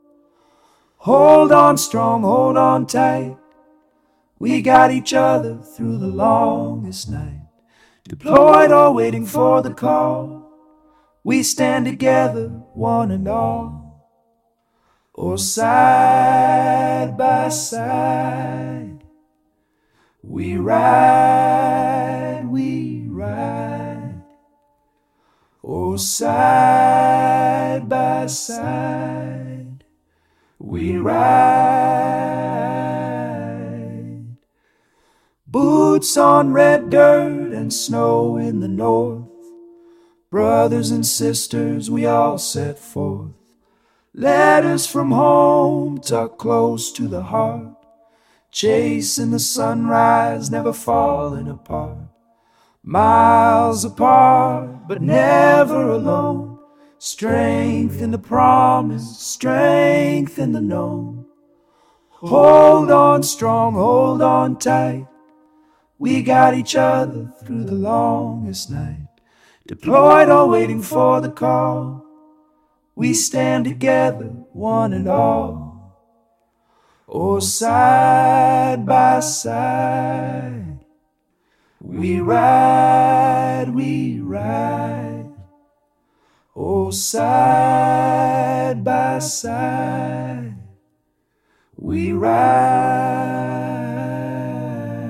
AI music created in the MusicKraze iPhone app
Tags: Country